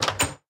Minecraft Version Minecraft Version latest Latest Release | Latest Snapshot latest / assets / minecraft / sounds / block / chest / open_locked.ogg Compare With Compare With Latest Release | Latest Snapshot
open_locked.ogg